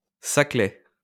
Saclay (French: [saklɛ]